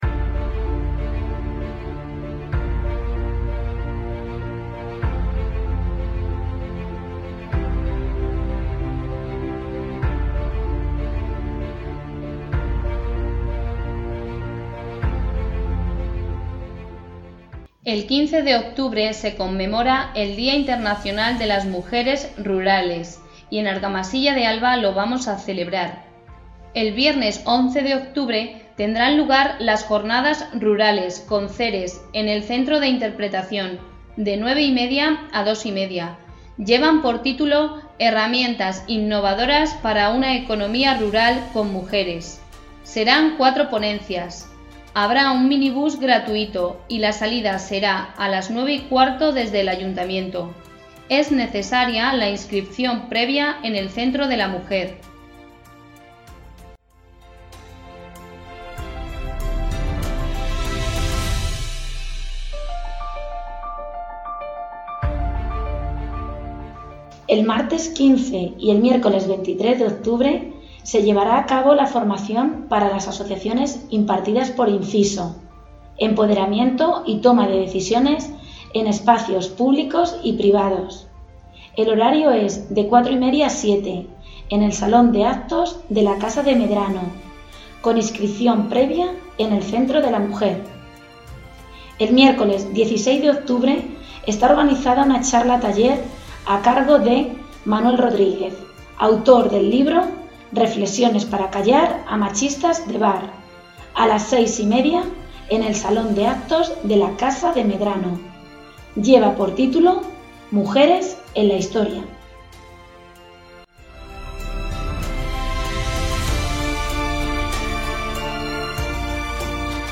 Anuncio de radio